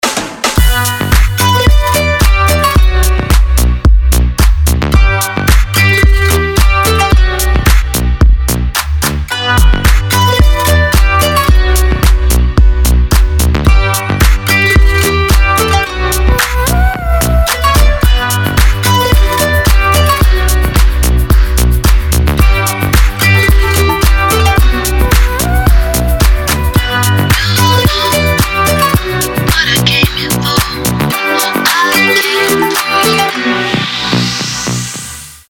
• Качество: 320, Stereo
deep house
dance
без слов
club
струнные